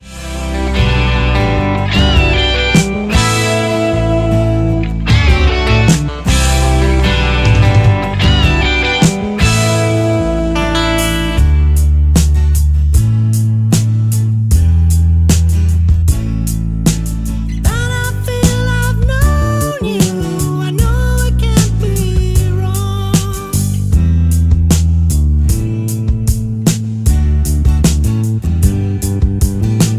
D
MPEG 1 Layer 3 (Stereo)
Backing track Karaoke
Rock, 1970s